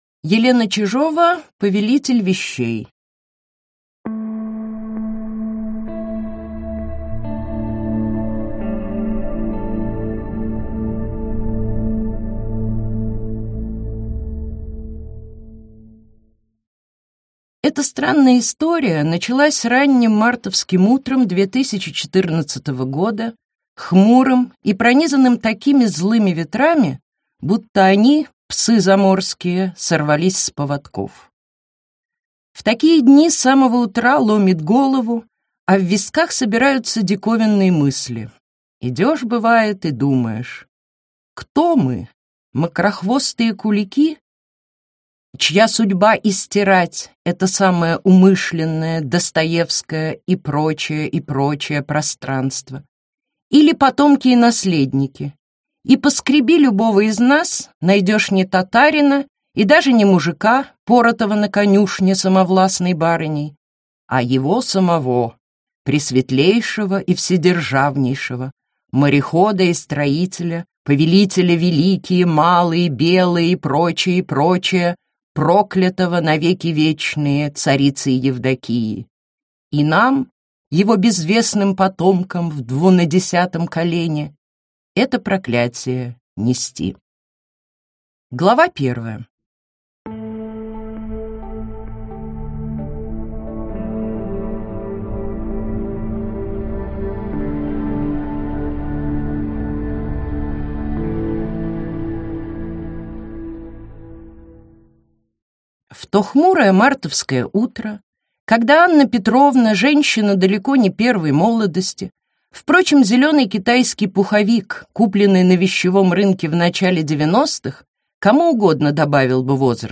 Аудиокнига Повелитель вещей | Библиотека аудиокниг